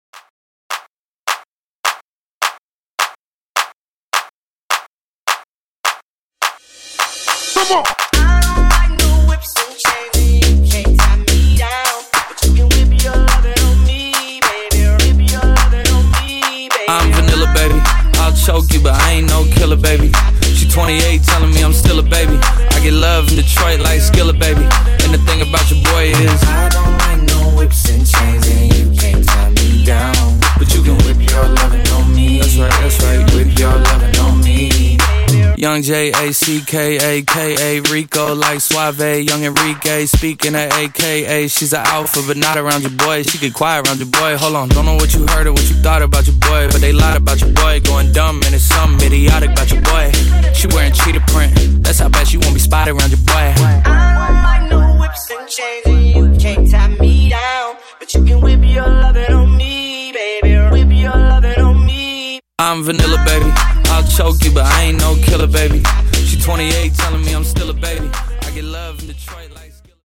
Clap In-Outro Edit)Date Added